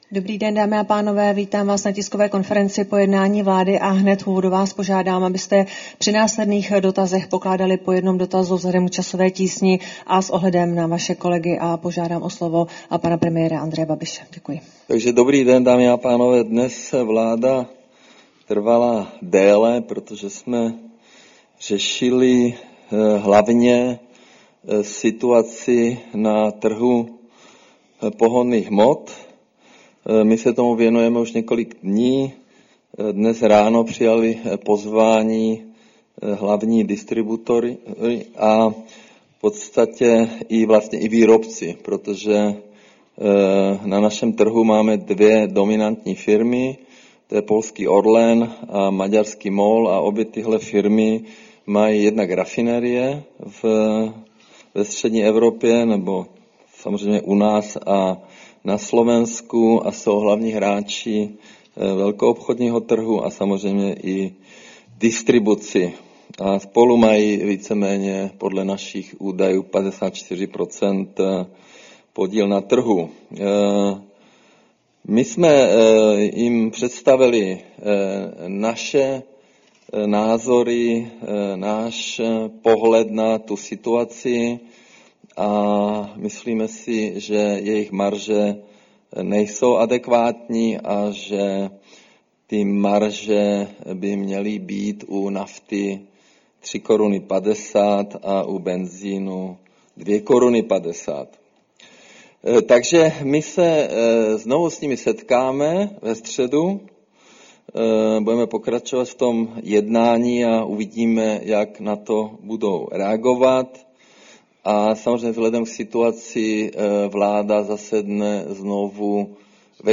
Tisková konference po jednání vlády, 30. března 2026